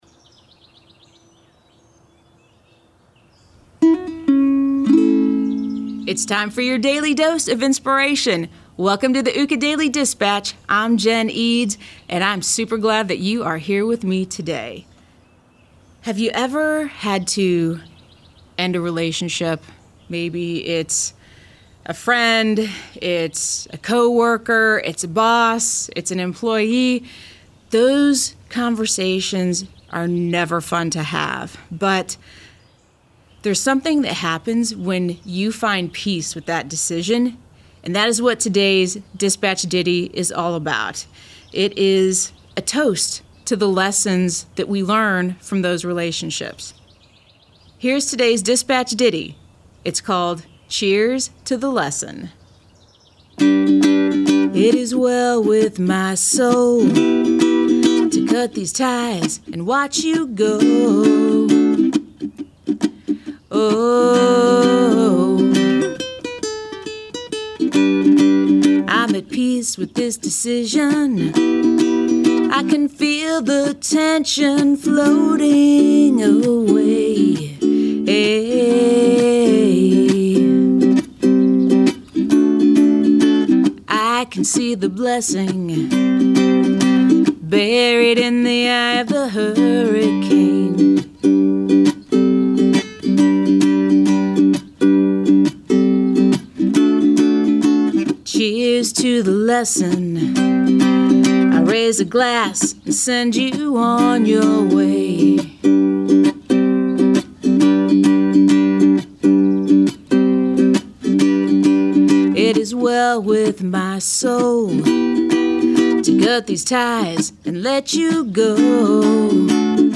Today's dispatch ditty is inviting you to recognize the lessons you learned from these relationships.